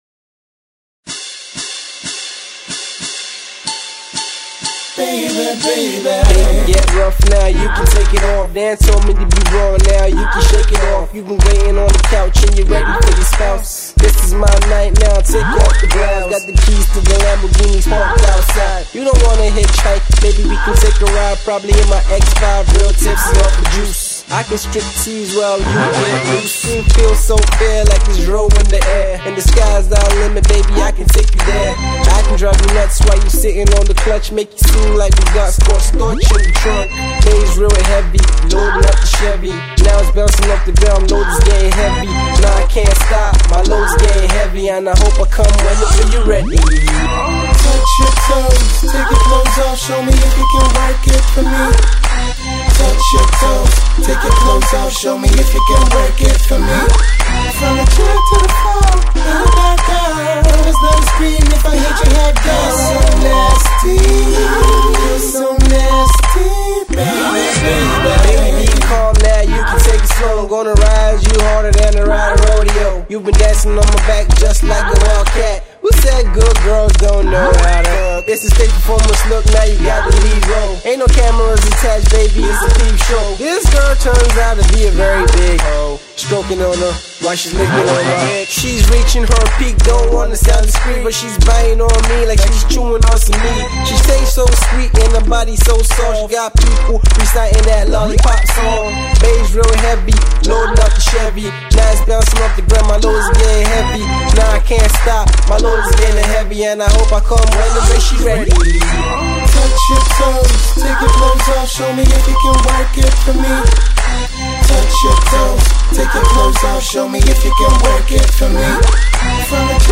up-and-coming rapper
With his smooth fluid flow and good wordplay
is a playful, entertaining song with truly explicit lyrics